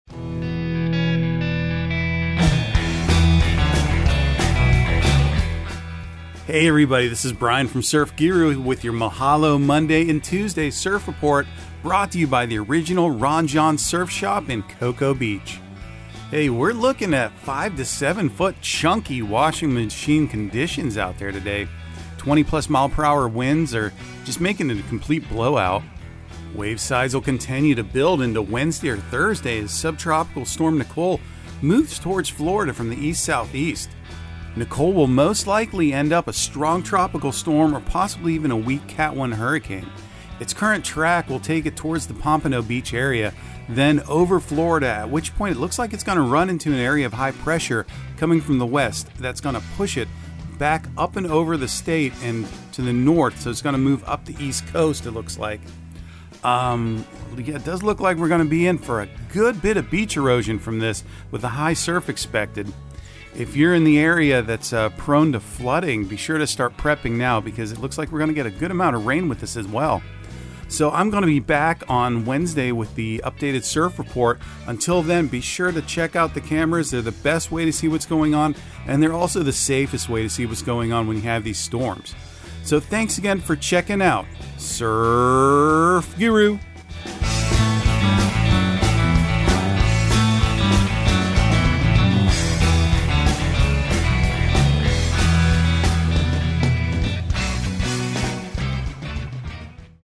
Surf Guru Surf Report and Forecast 11/07/2022 Audio surf report and surf forecast on November 07 for Central Florida and the Southeast.